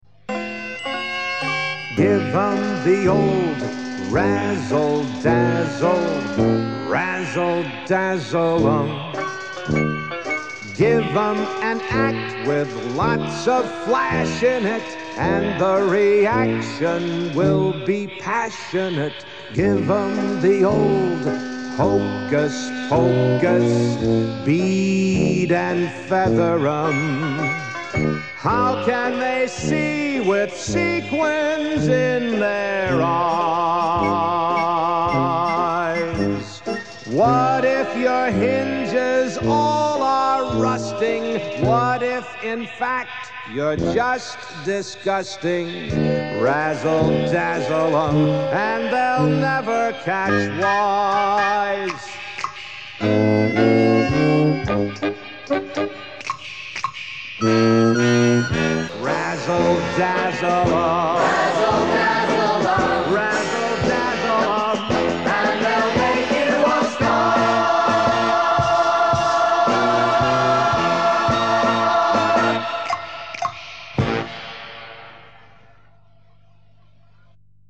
Rehearsal & Practice Music